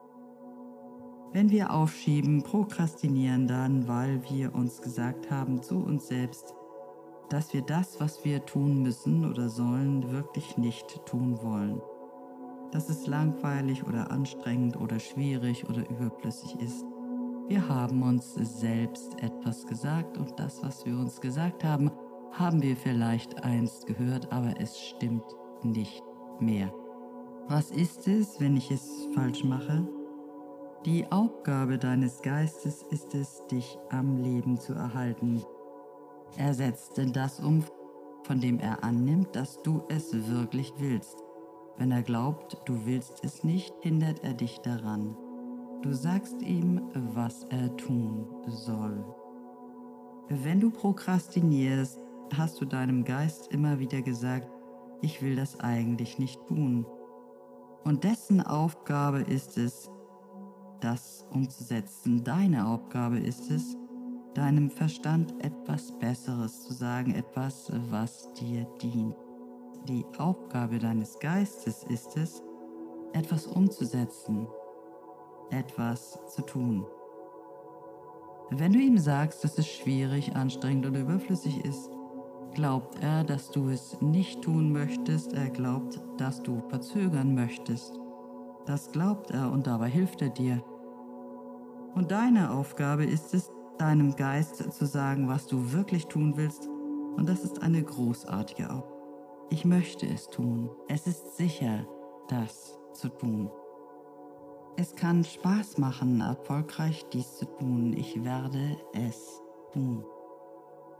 Meditation zum Buch:
Prokrastination-Final-Musik-R3-PS.wav